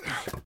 take_wood.ogg